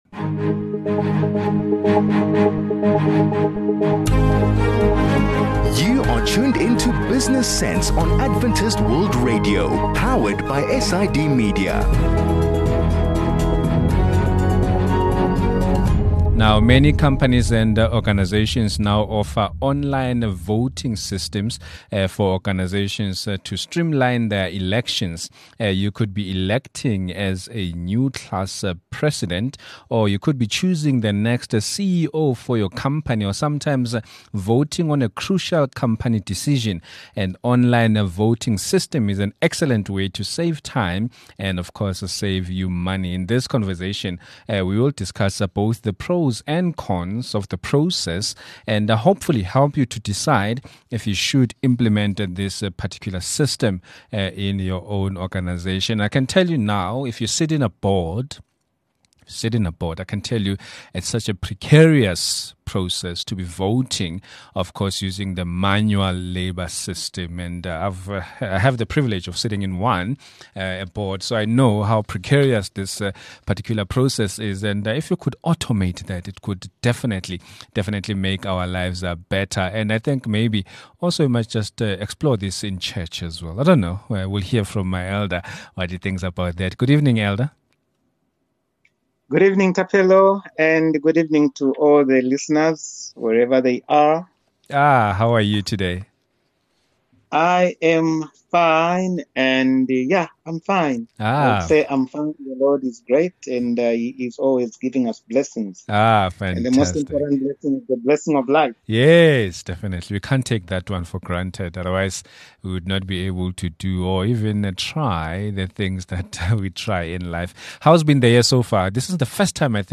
This conversation will discuss both the pros and cons of the electronic voting process, to hopefully help you decide if you should implement the system in your own organization.